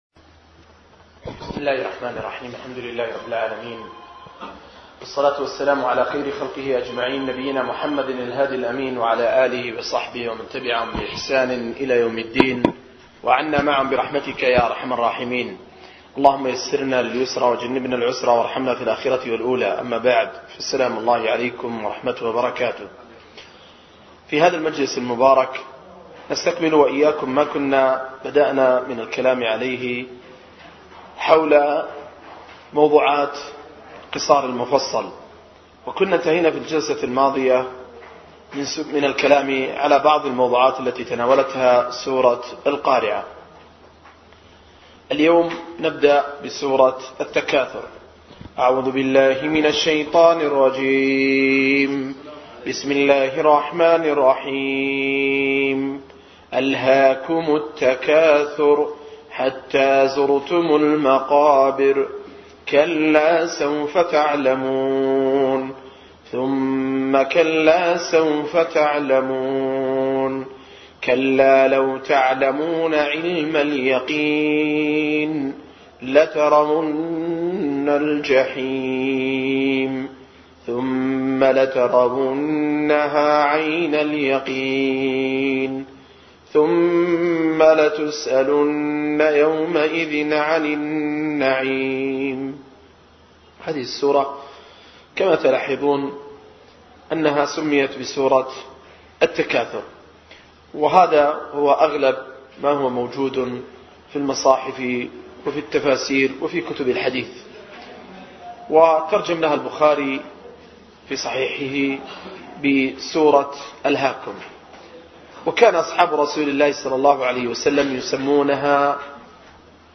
021- التفسير الموضوعي الميسر لقصار المفصل – الدرس الحادي والعشرون